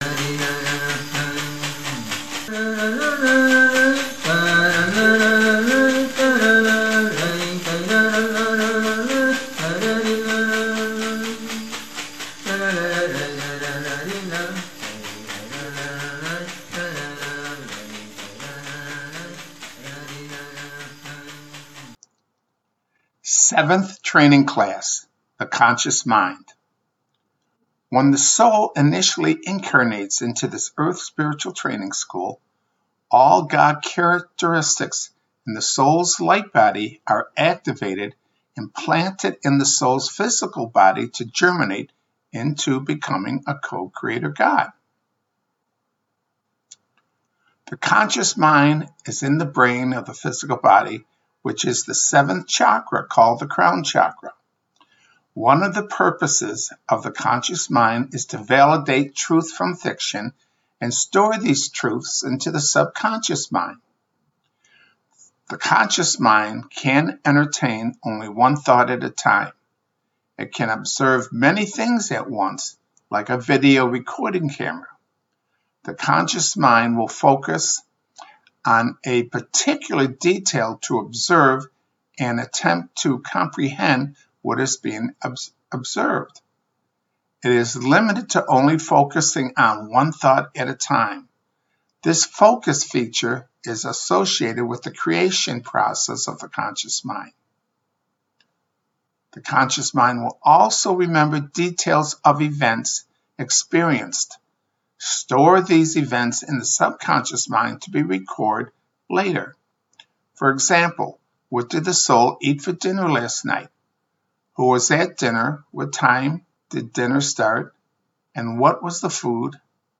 7th-Training-Class-The-Conscious-Mind.mp3